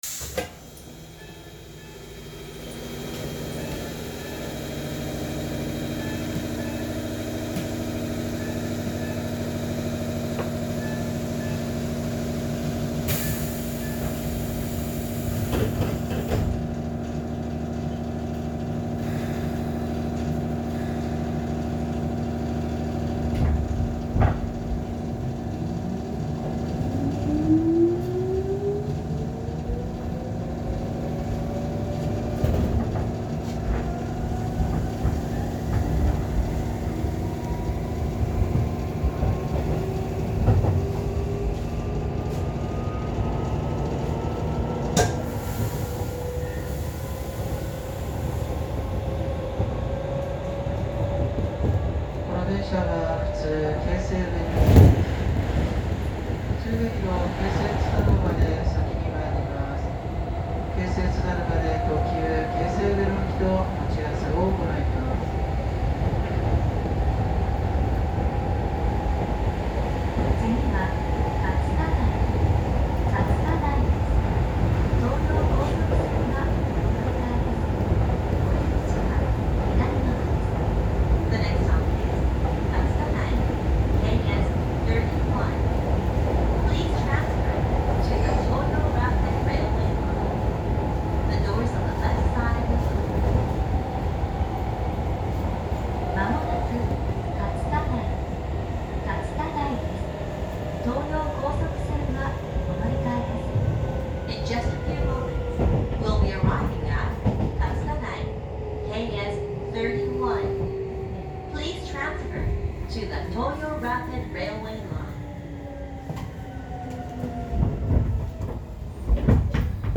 ・3600形走行音
〜自動放送導入後〜
3668Fを除いた3600形はチョッパ制御。基本的には3400形と同じ音です。
3688_Shizu-Katsutadai.mp3